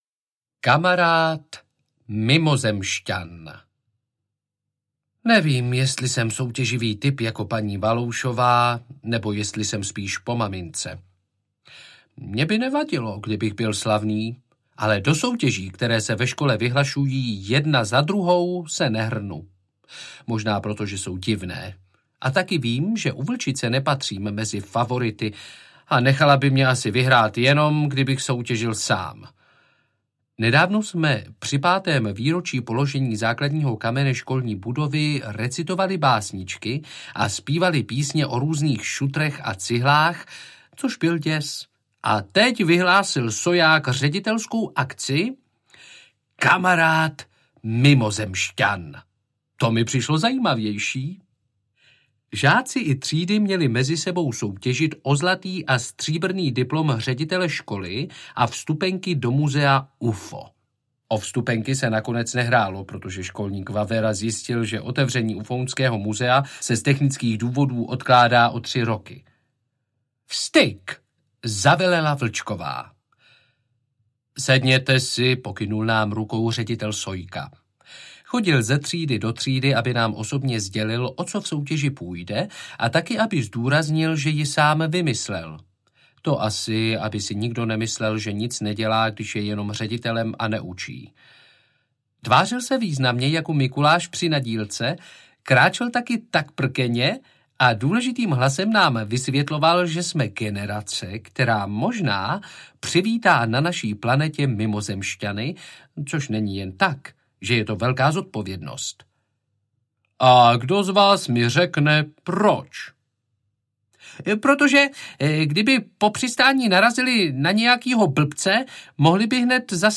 Bouráci audiokniha
Ukázka z knihy